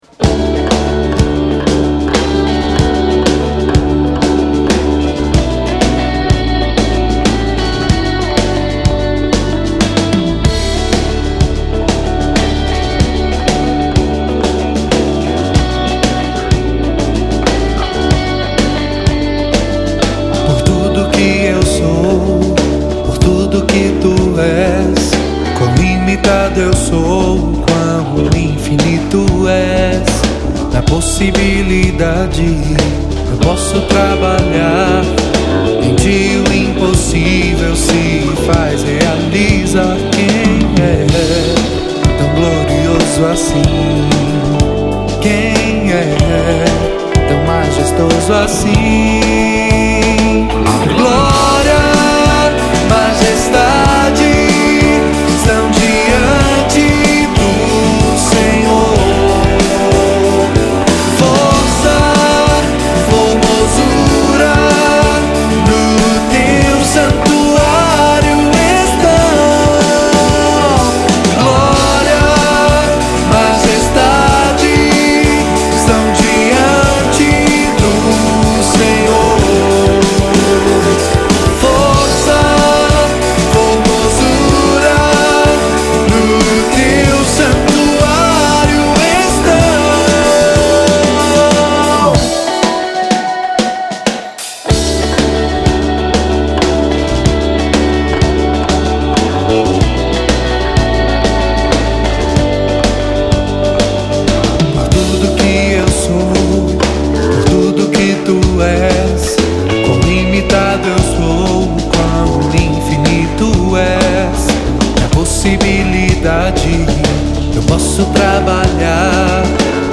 ministério de louvor
baixo
bateria
violão e back vocal
teclados
guitarras